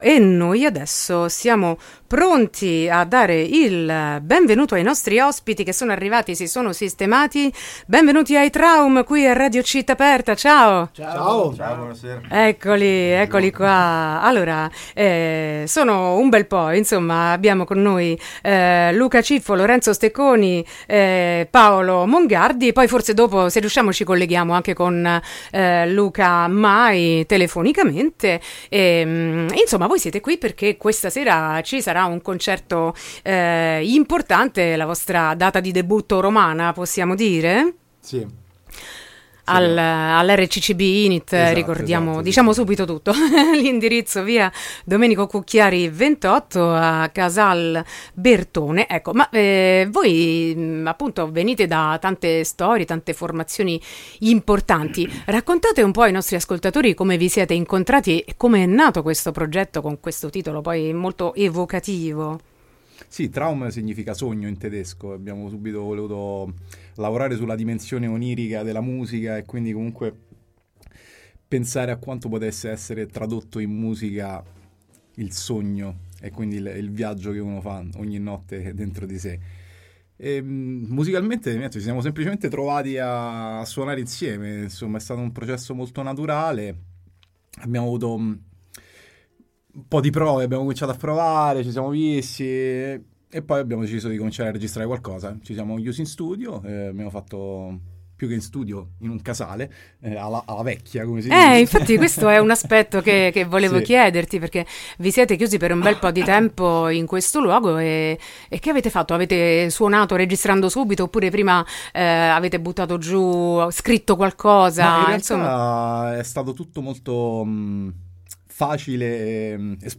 Intervista ai Traum | Radio Città Aperta